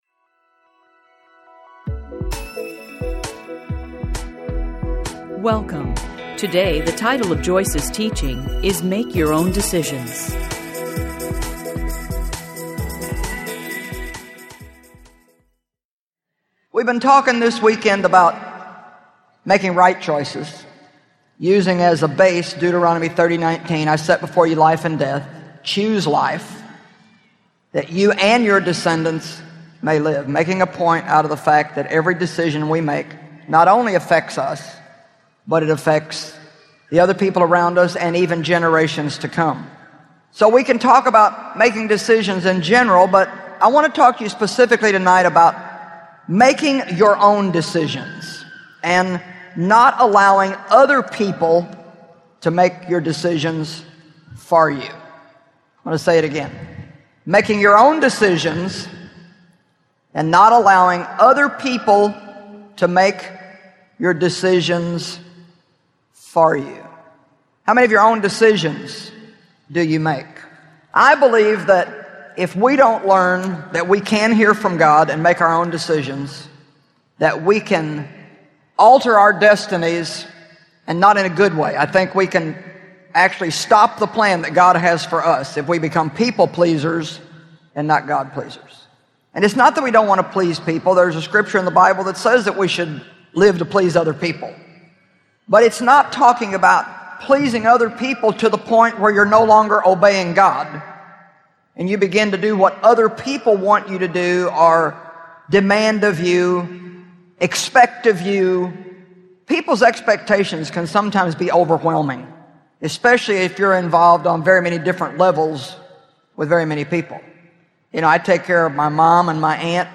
The Wisdom of Making Right Choices Teaching Series Audiobook
Narrator
3.90 Hrs. – Unabridged